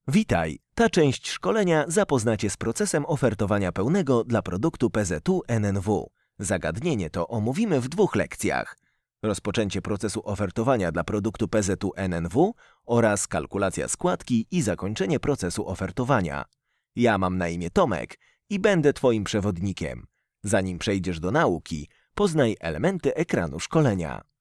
Polish voiceover with dubbing and acting expierence.
Sprechprobe: eLearning (Muttersprache):